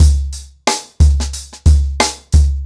TheStakeHouse-90BPM.31.wav